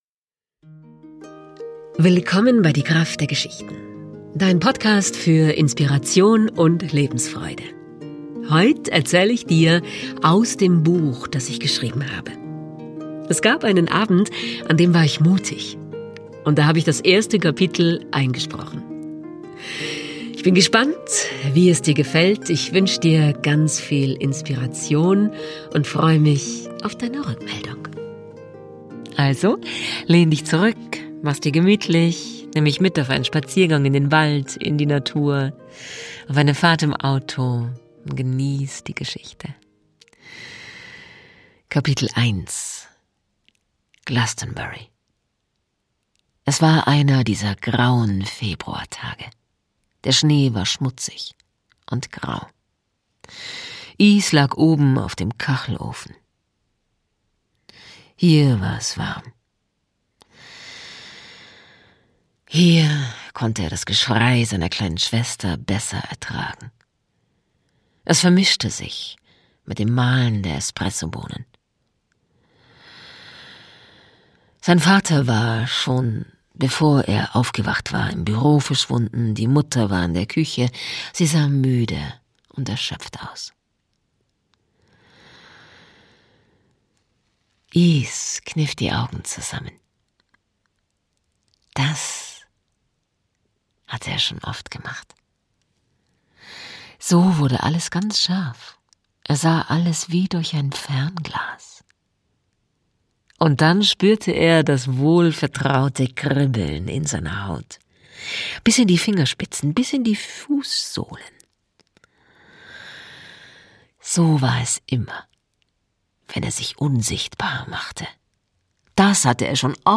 Da hab ich das erste Kapitel von meinem Buch einfach eingesprochen.